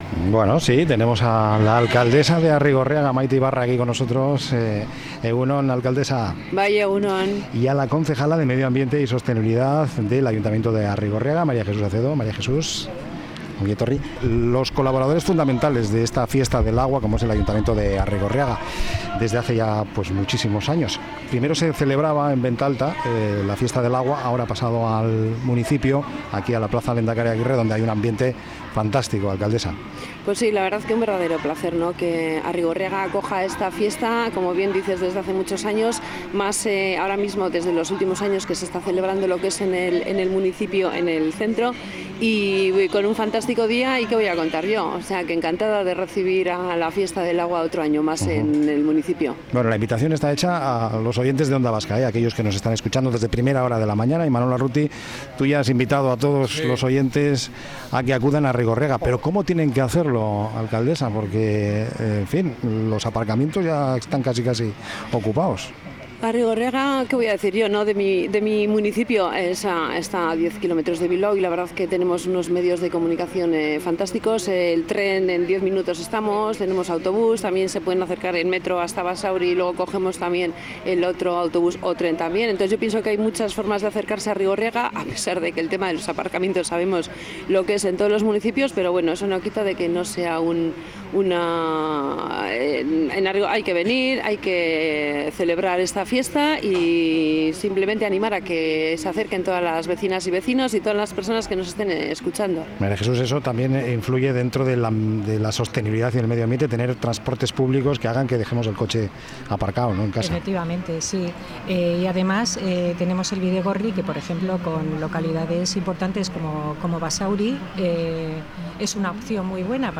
Fiesta del Agua| Entrevista